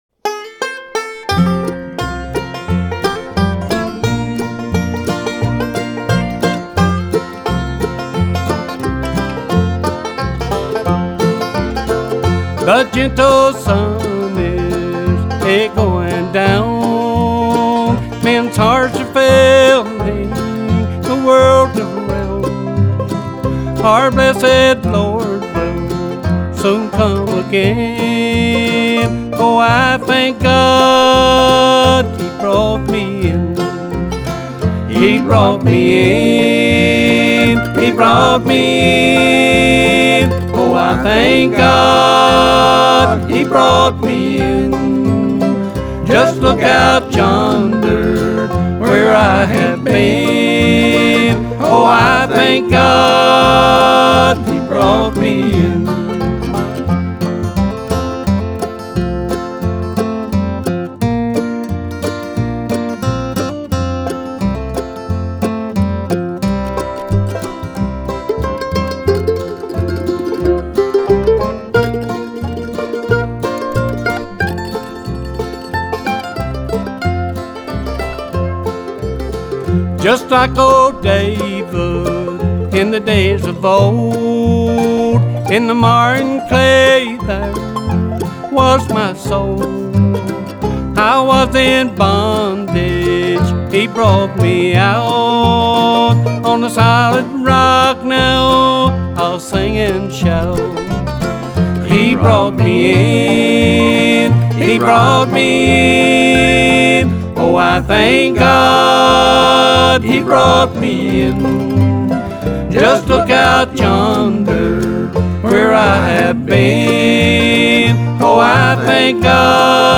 This is American traditional music at its very best.